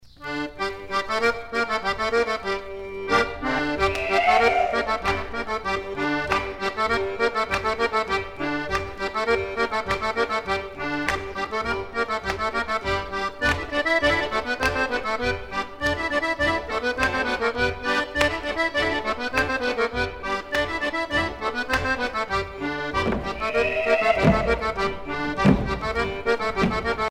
danse : rigodon
Pièce musicale éditée